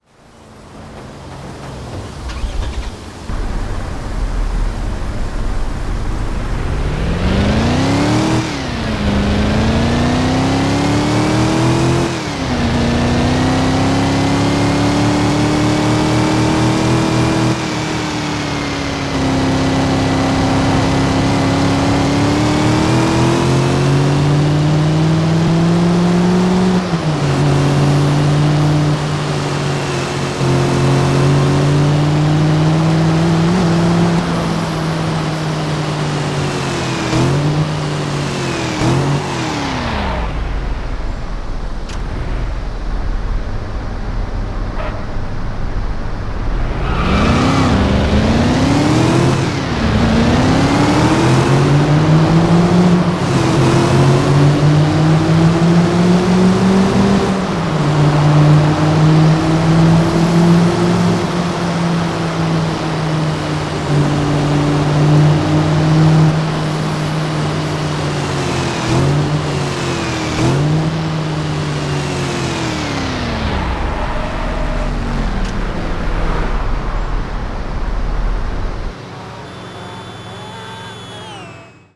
tdu_saleen_s302_insane_coupe_1-1_stargt.mp3